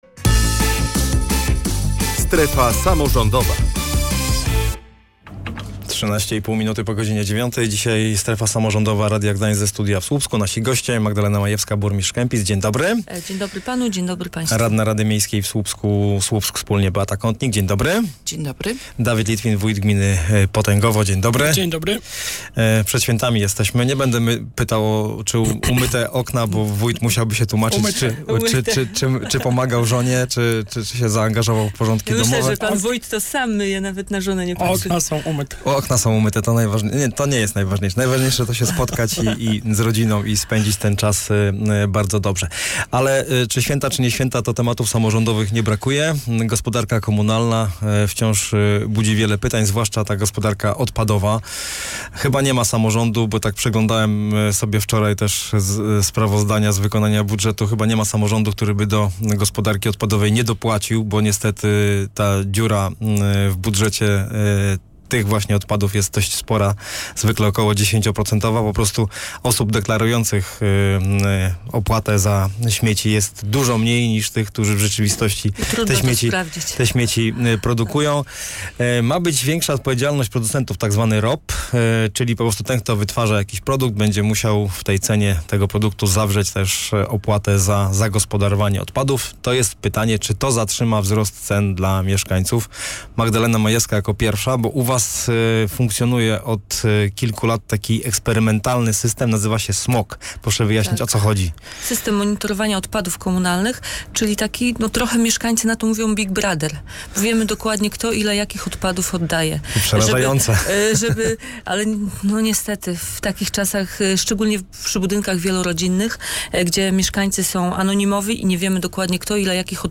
Ten, kto wytwarza jakiś produkt, będzie musiał w jego cenie zawrzeć też opłatę za zagospodarowanie odpadów. Czy to zatrzyma wzrost cen dla mieszkańców? O tym rozmawialiśmy w „Strefie Samorządowej”.